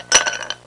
Ice Cubes In Glass Sound Effect
Download a high-quality ice cubes in glass sound effect.
ice-cubes-in-glass-1.mp3